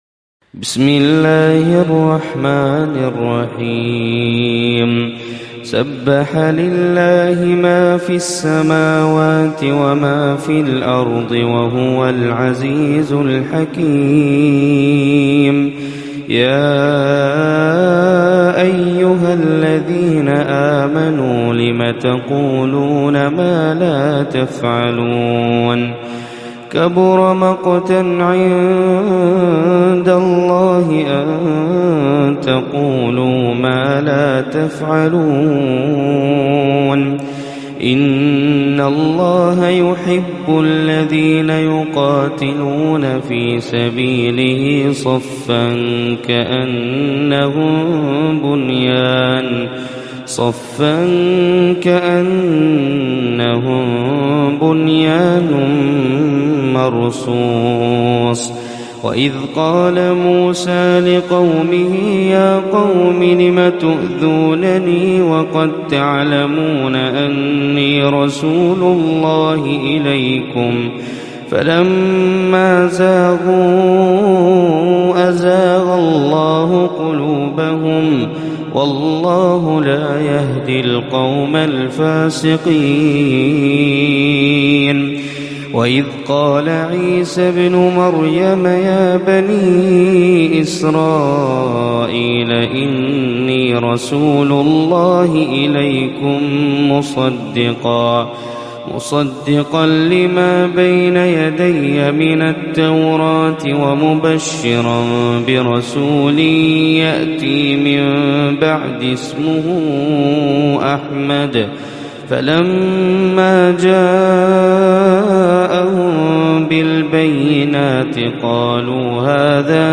Surat Al Saff Reciter Hazza Alblushi